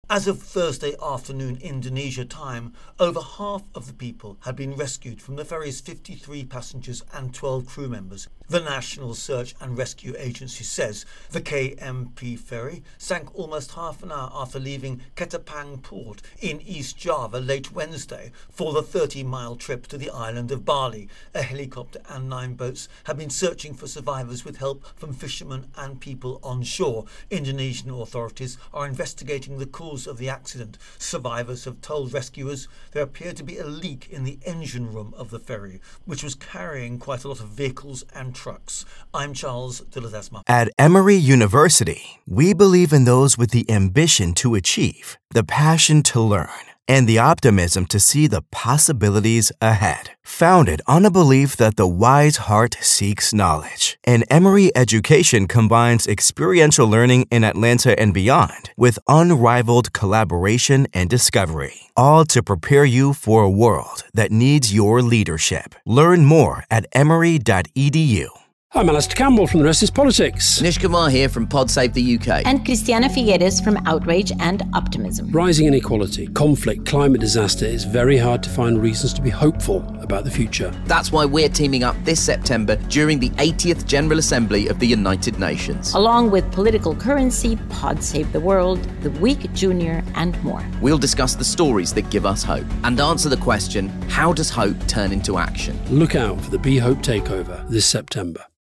Latest Stories from The Associated Press / Indonesia rescuers find 31 survivors in ongoing search after ferry sinks near Bali